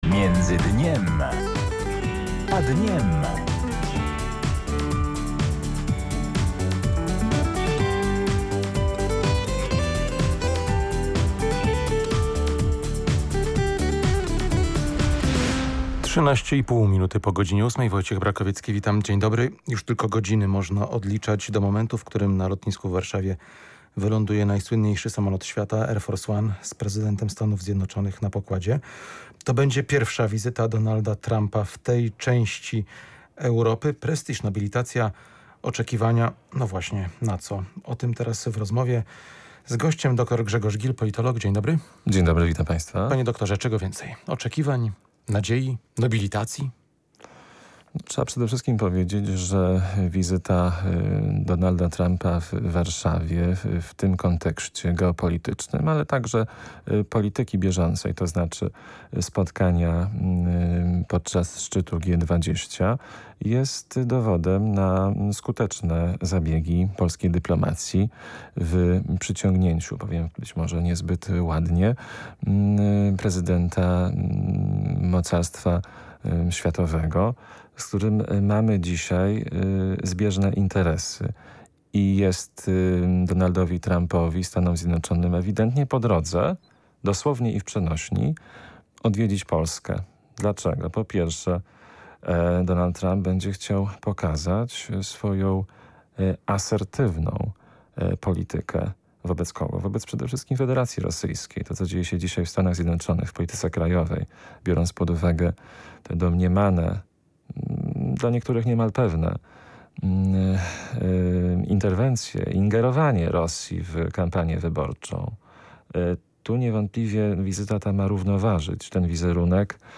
mówił na antenie Polskiego Radia Lublin